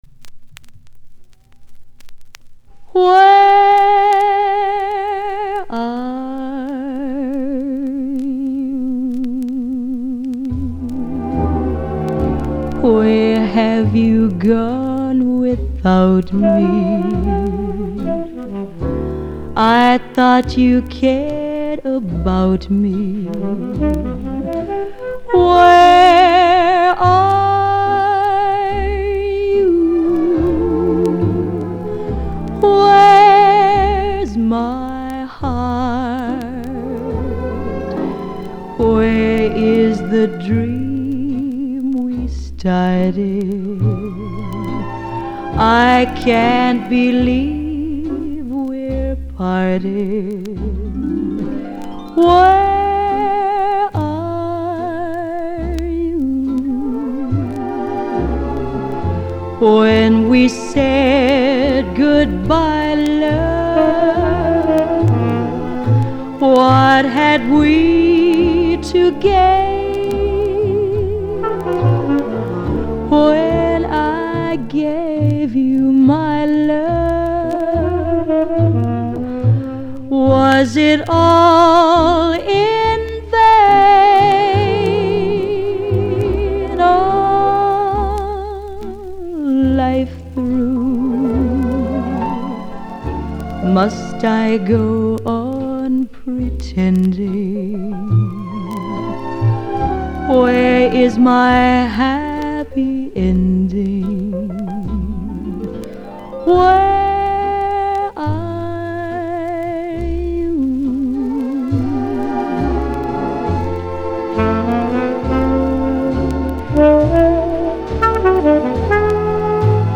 형식:레코드판, LP, Album, Mono
장르:Pop 1955.